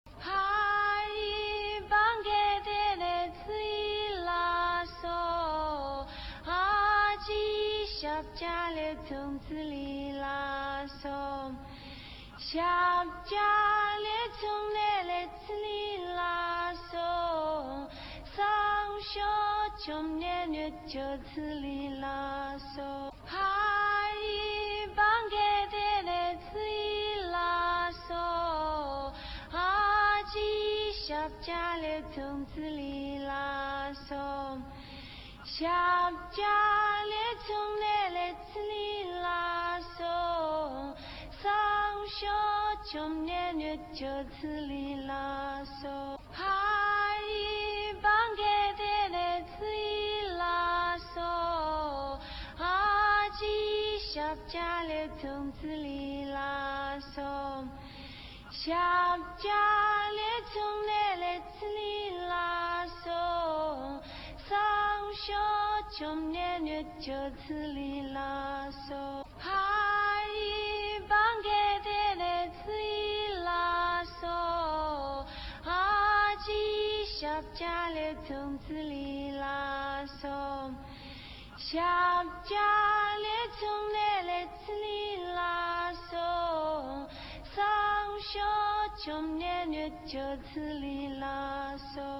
[17/1/2010]在那草地上-----宁静纯音无伴奏 激动社区，陪你一起慢慢变老！